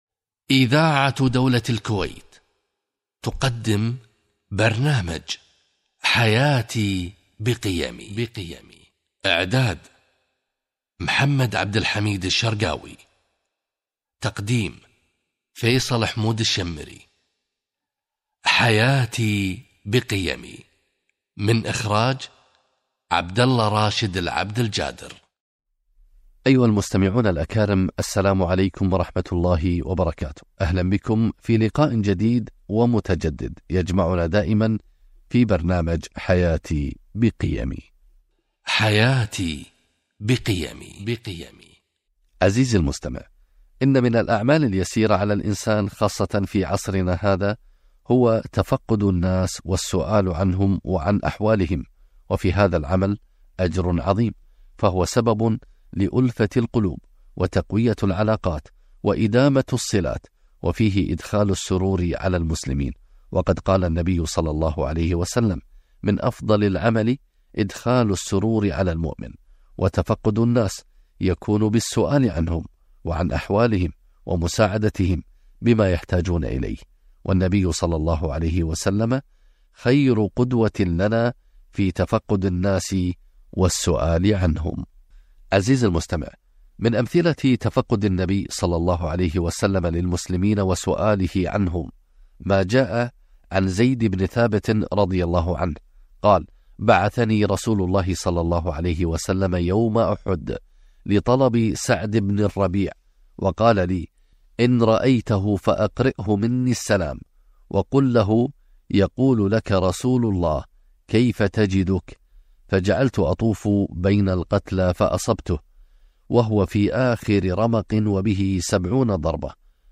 أهمية تفقد الناس - لقاء إذاعي عبر برنامج حياتي قيمي